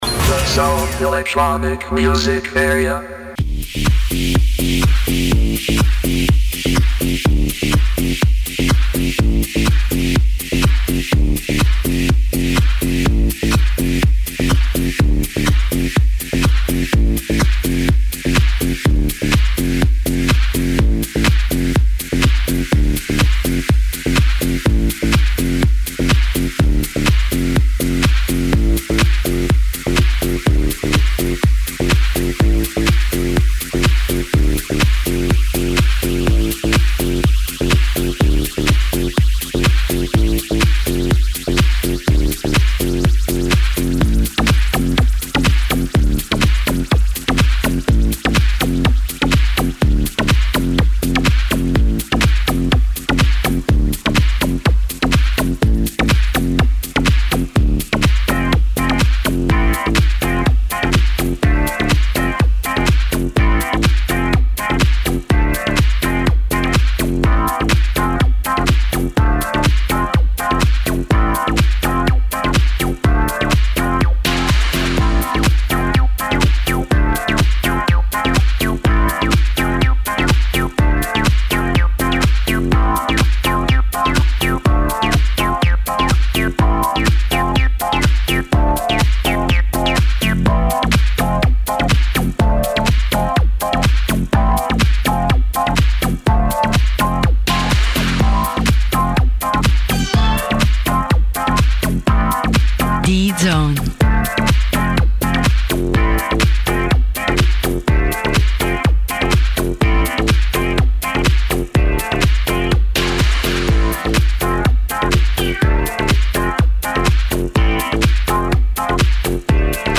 ON-AIR LIVE